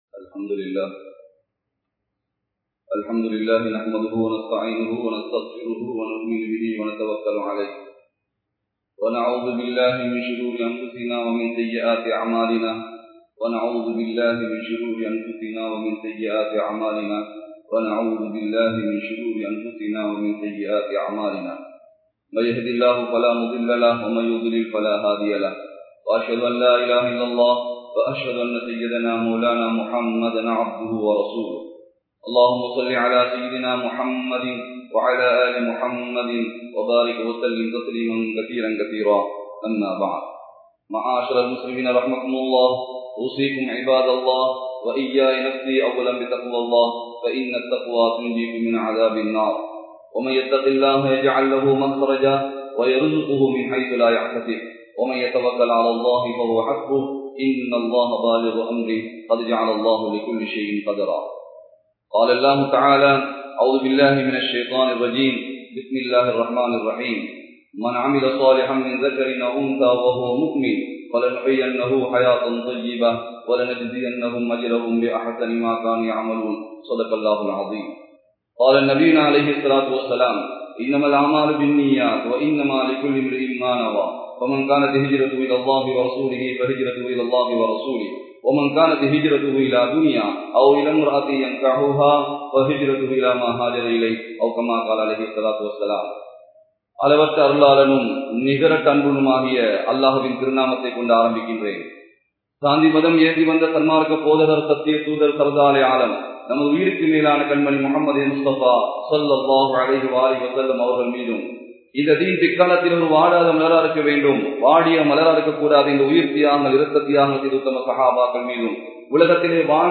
Nallavarhalin Adaiyalangal (நல்லவர்களின் அடையாளங்கள்) | Audio Bayans | All Ceylon Muslim Youth Community | Addalaichenai